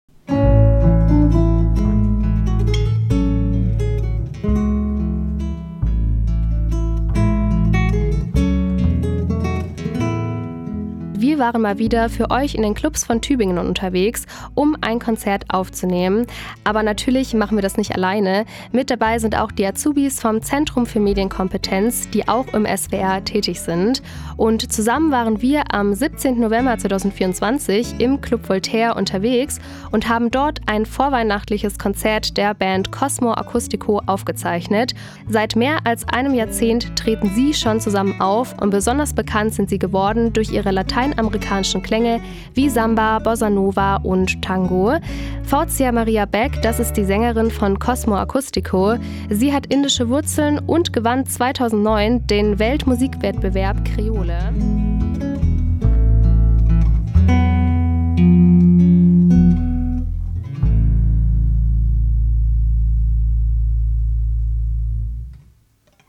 Heute bringen wir den ersten Teil des Auftritts.
Kontrabass
Gitarre, Tres cubano
Perkussion, Gesang
Gesang, Perkussion